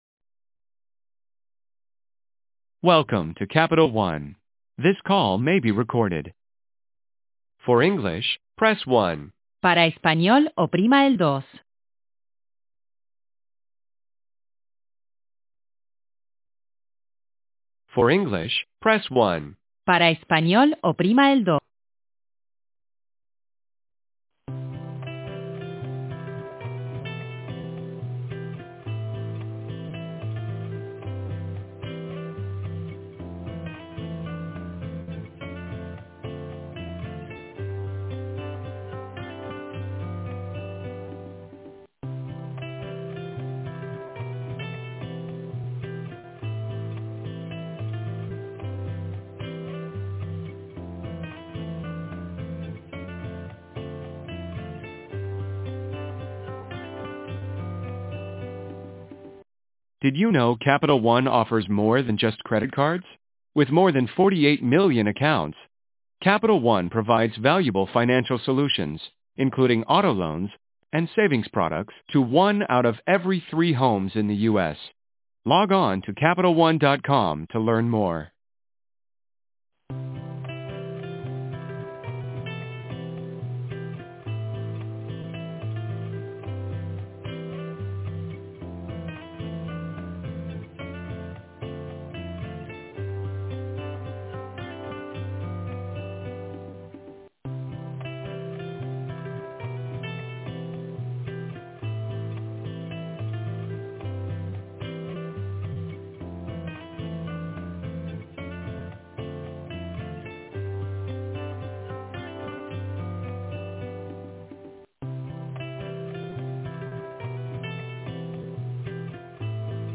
You hear the ViciDial “POP” when they call and usually hear nothing afterwards when the robo voicemail message fails to play, then it hangs up 30 seconds later. When you call the number back you are greeted with this complete garbage.